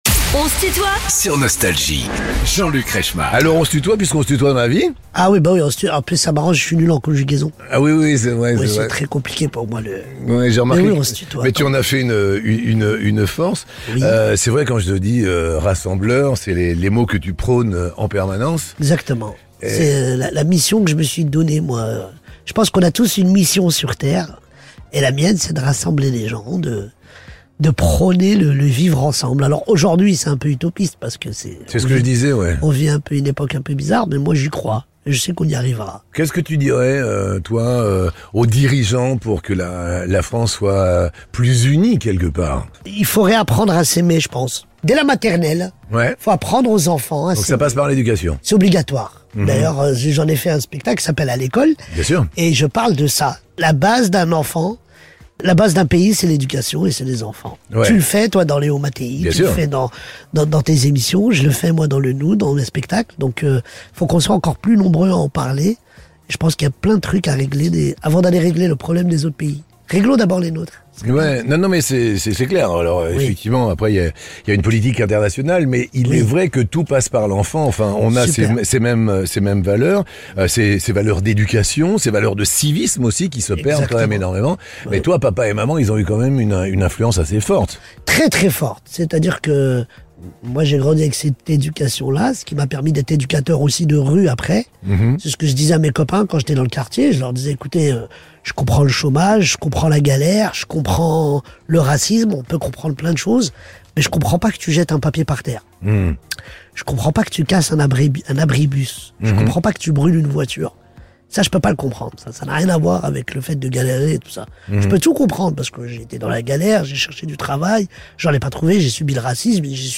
Humoriste attachant au physique atypique, Booder est l'invité de "On se tutoie ?..." avec Jean-Luc Reichmann